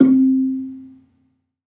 Gambang-C3-f.wav